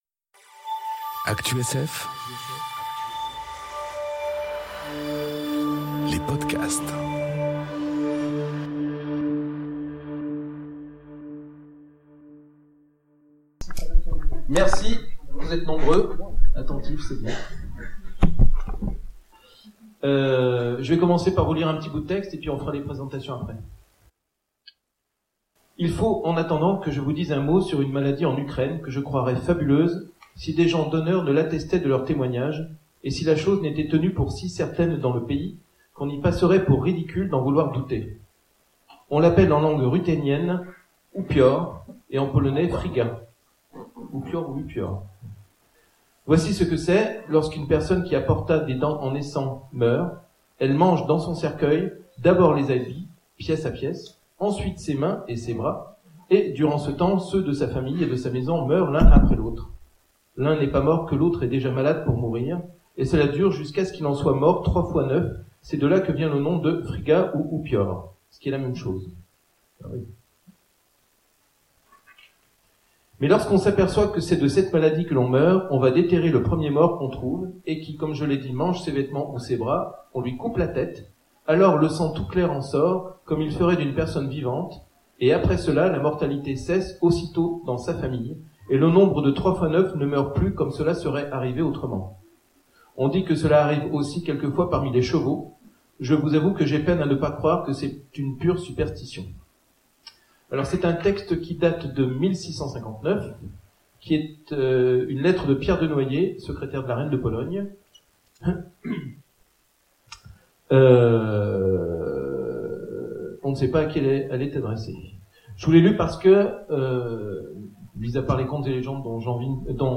Aventuriales 2018 : Conférence Genèse du vampirisme...